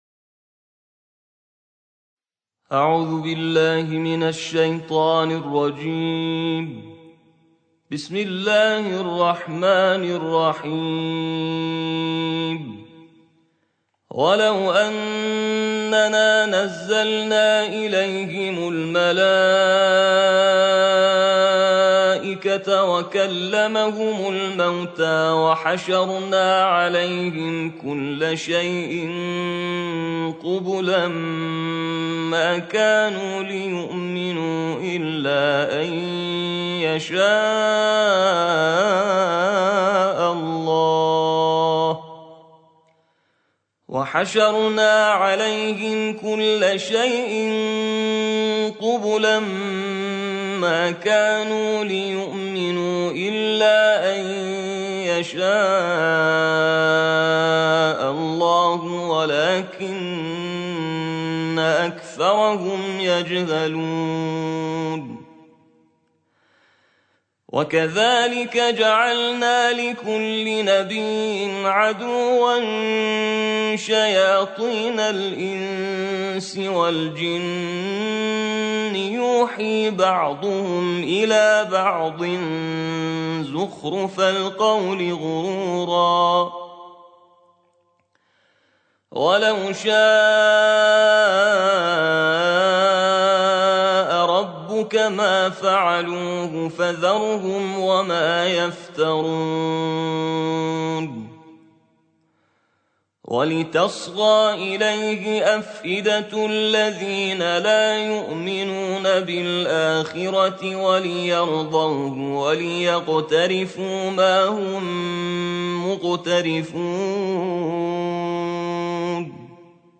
بامداد/ترتیل جزء هشتم قرآن کریم/ با ظاهری آراسته به مسجد برو+صوت و متن آیات